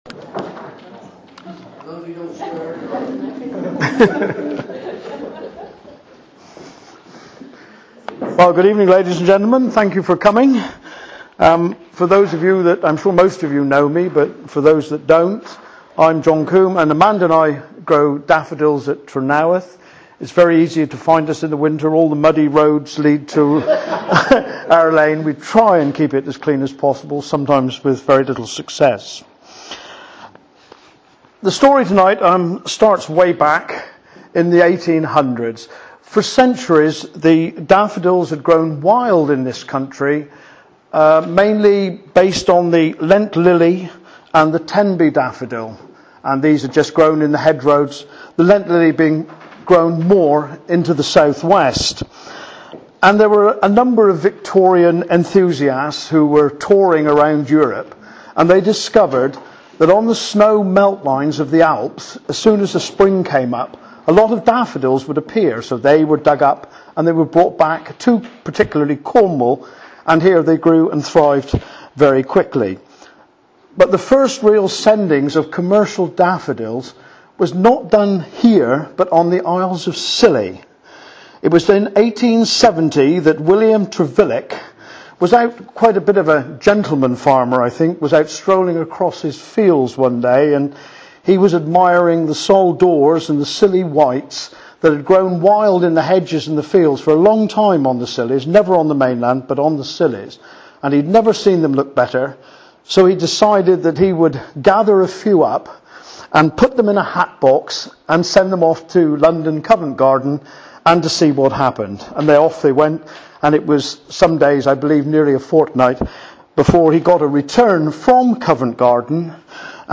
Recordings of talks and interviews of events and observations occurring in and around St Keverne Cornwall